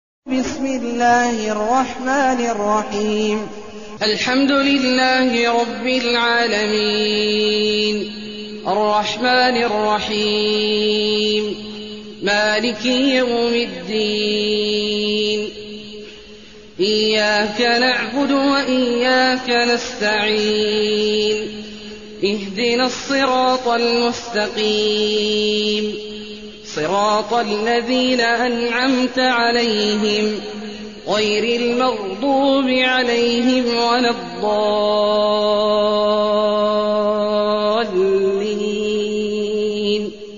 المكان: المسجد الحرام الشيخ: عبد الله عواد الجهني عبد الله عواد الجهني الفاتحة